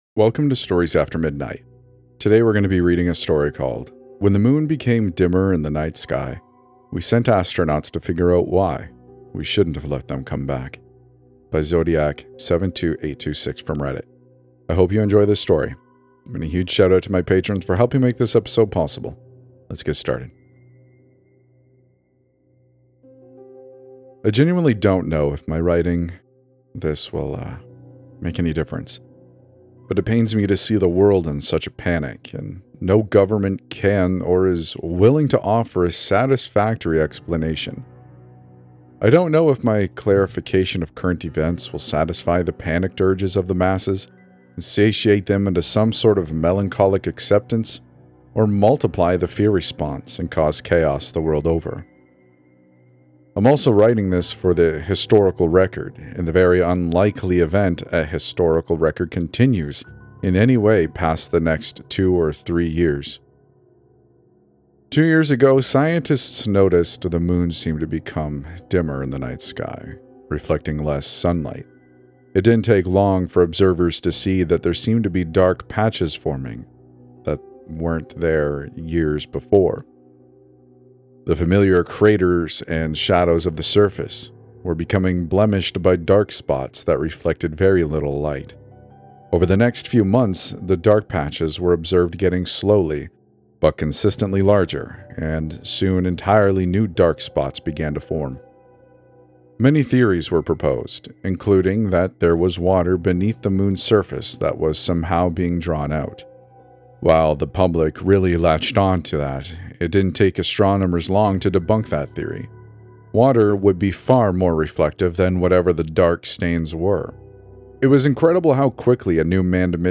E509 | The Moon is Disappearing | Horror Fiction | Not AI